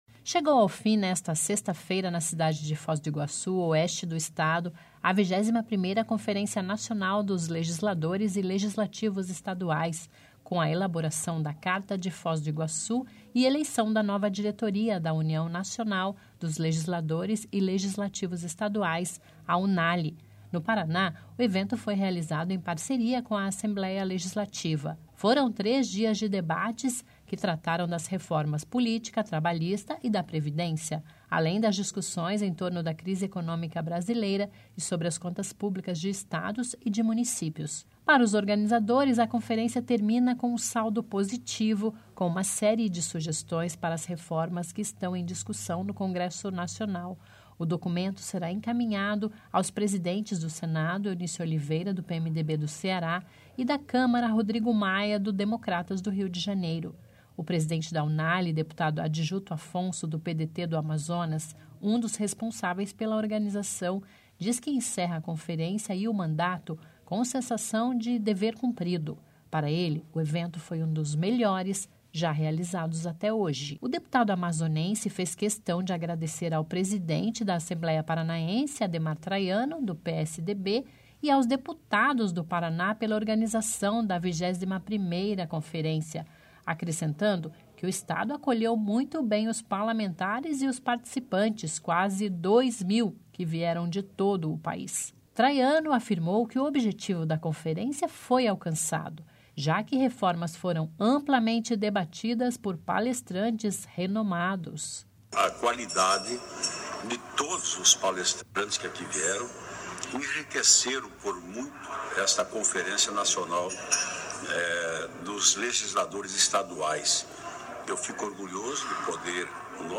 por Narração